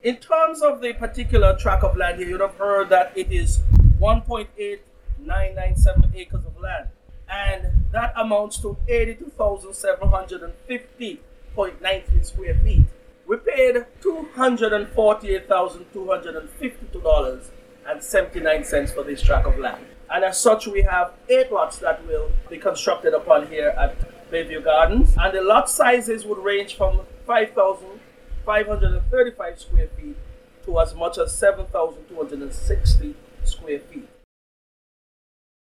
Deputy Premier and Minister of Lands and Housing, Alexis Jeffers, a ground breaking ceremony on Thursday 18th Nov., provided these specifics about the development.
Minister Alexis Jeffers.